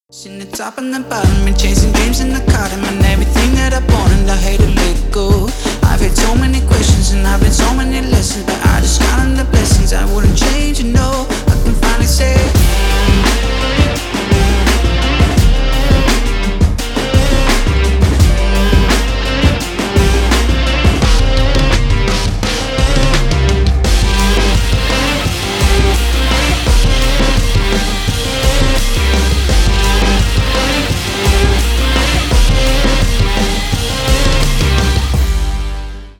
• Качество: 320 kbps, Stereo
Поп Музыка